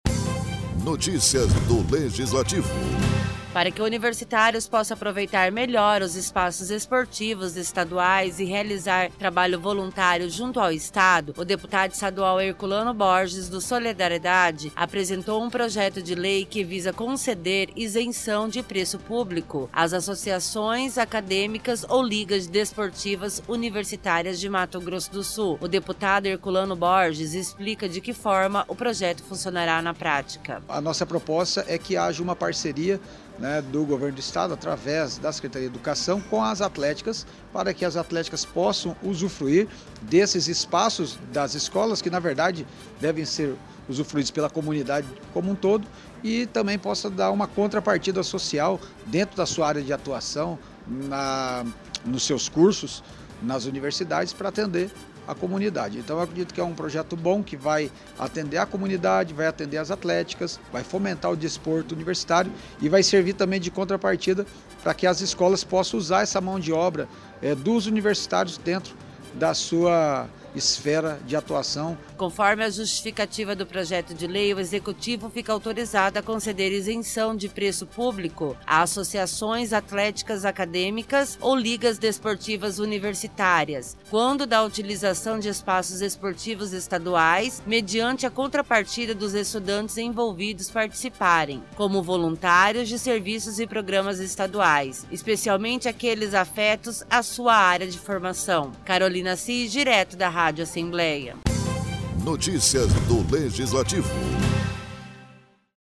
Locução e Produção: